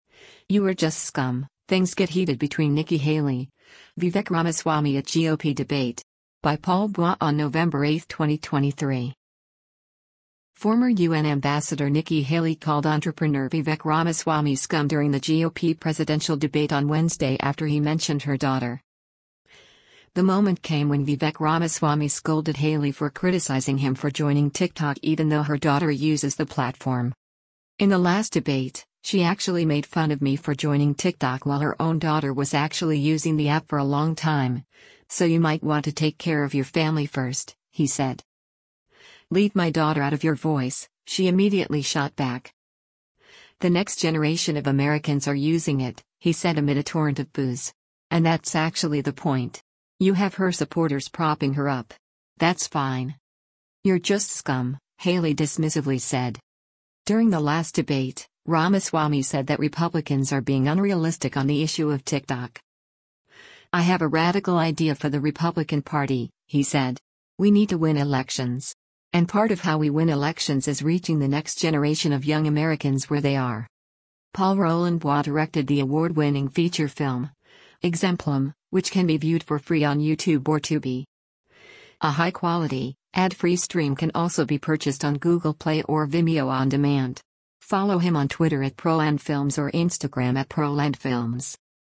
Former UN Ambassador Nikki Haley called entrepreneur Vivek Ramaswamy “scum” during the GOP presidential debate on Wednesday after he mentioned her daughter.
“The next generation of Americans are using it,” he said amid a torrent of boos.
“You’re just scum,” Haley dismissively said.